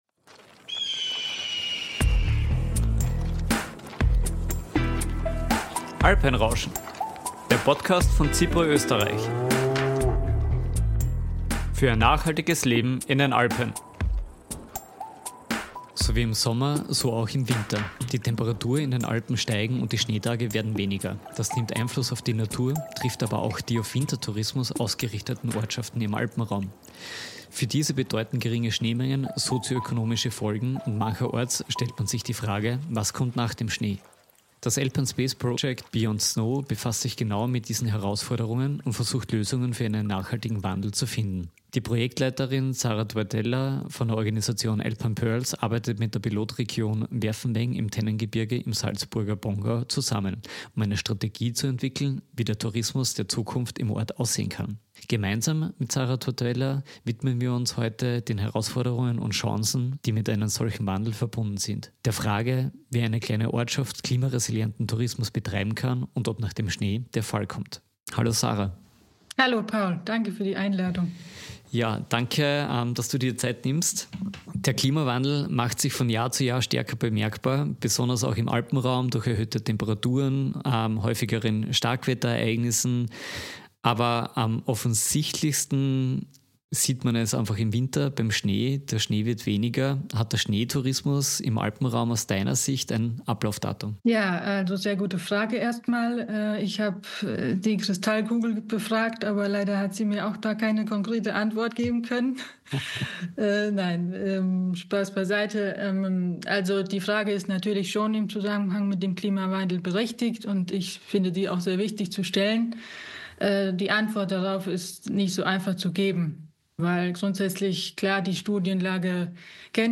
Das Alpine Space Projekt „BeyondSnow“ befasst sich mit den sozioökonomischen Folgen für Wintertourismusorte und Strategien für einen klimaresilienten Tourismus. Im Gespräch